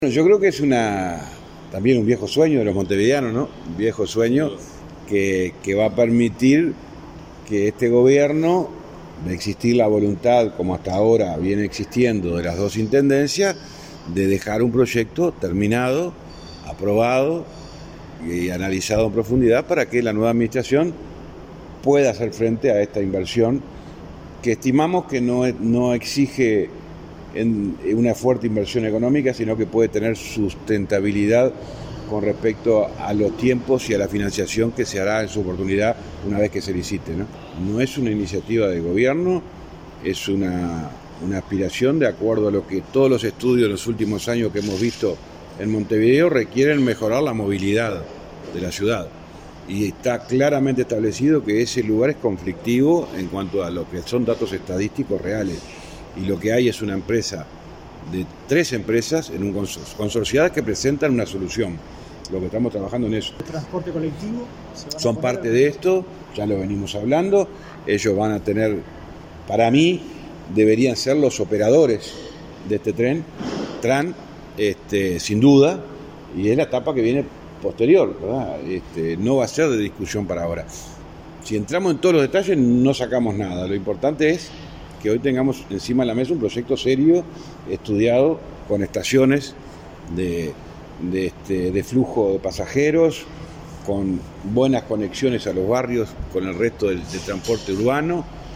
El ministro de Transporte y Obras Públicas, José Luis Falero destacó en rueda de prensa que “es un viejo sueño de los montevideanos”.
FALERO-tranvia.mp3